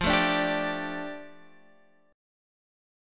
F6add9 chord